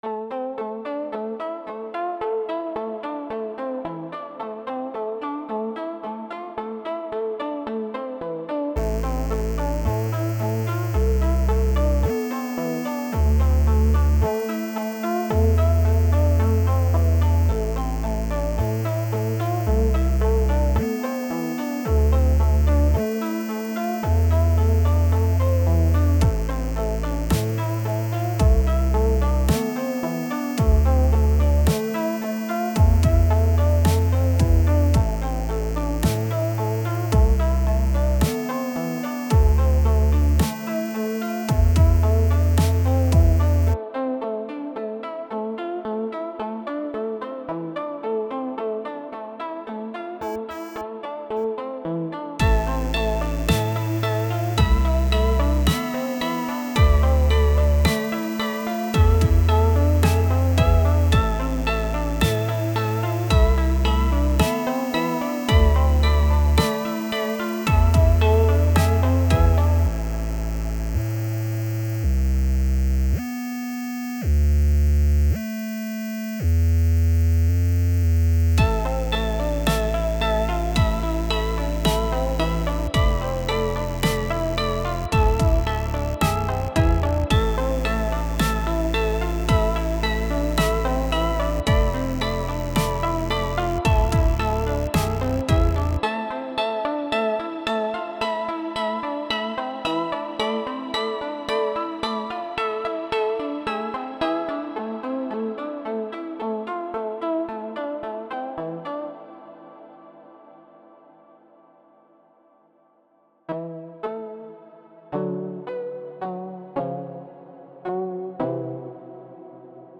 Fat stinky square base!
bass music plink synth